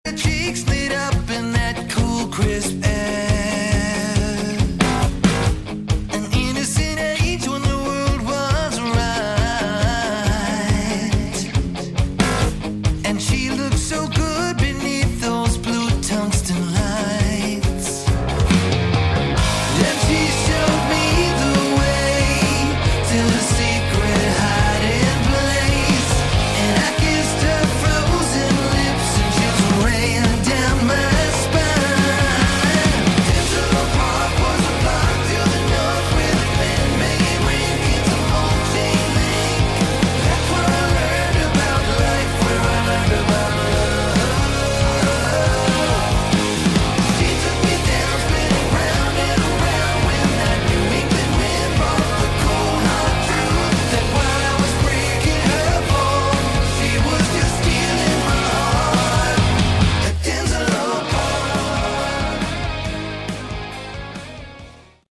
Category: Melodic Rock